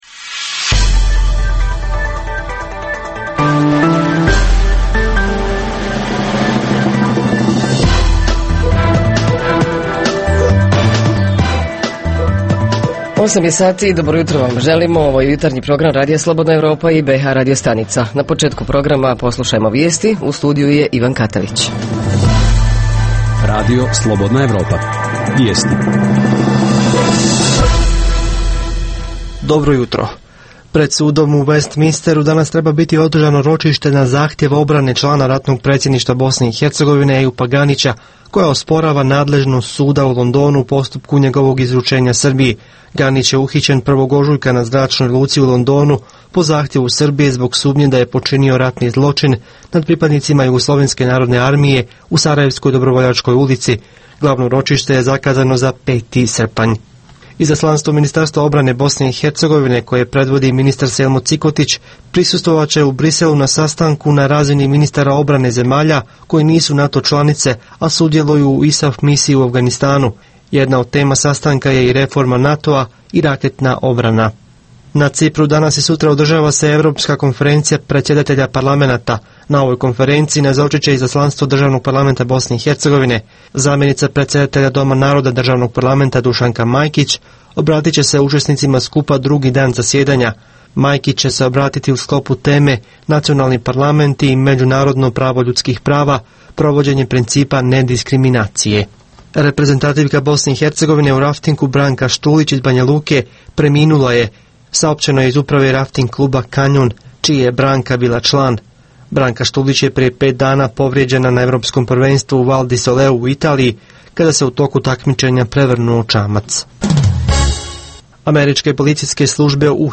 Ovog jutra govorimo o tome kakvu vodu pijemo, kakve je kvalitete, ko je kontroliše i kako često, itd. Reporteri iz cijele BiH javljaju o najaktuelnijim događajima u njihovim sredinama.
Redovni sadržaji jutarnjeg programa za BiH su i vijesti i muzika.